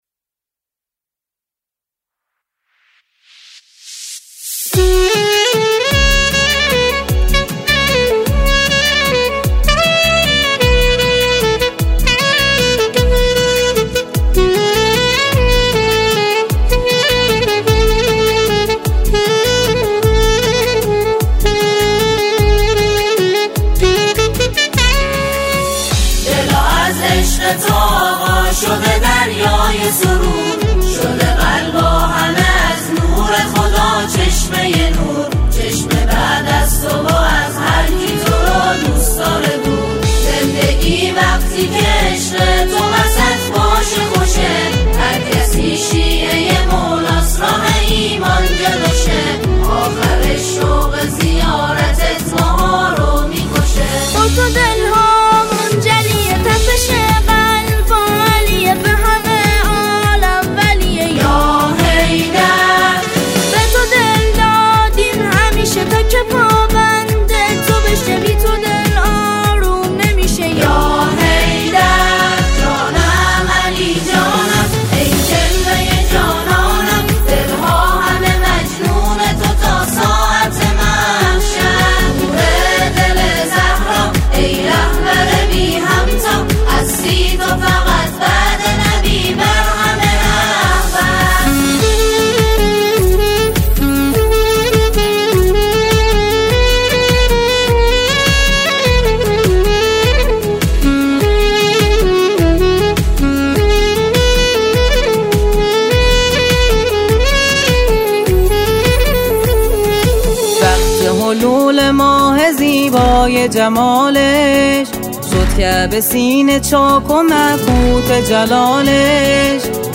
به صورت جمع خوانی